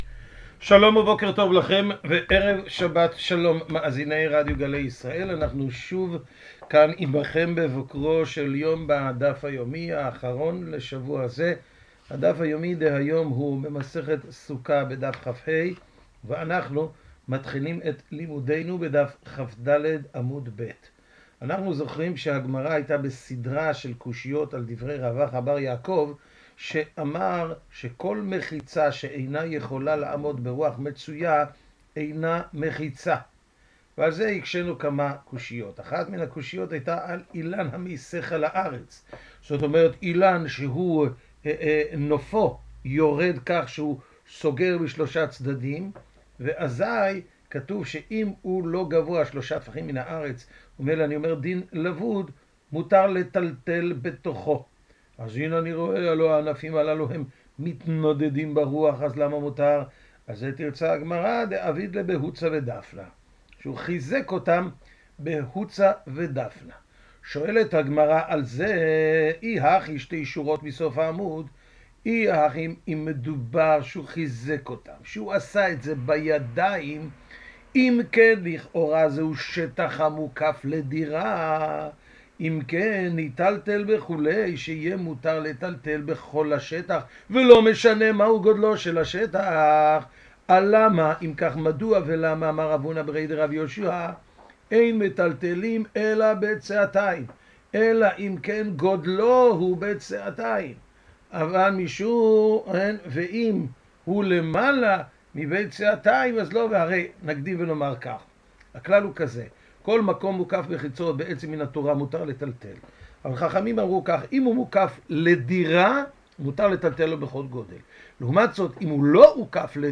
השיעור משודר בשעה 05:30 בבוקר ברדיו גלי ישראל וכל היום באתר סרוגים. האזנה ערבה ולימוד פורה